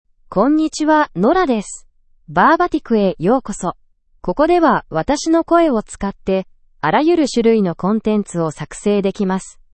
Nora — Female Japanese (Japan) AI Voice | TTS, Voice Cloning & Video | Verbatik AI
NoraFemale Japanese AI voice
Nora is a female AI voice for Japanese (Japan).
Voice sample
Listen to Nora's female Japanese voice.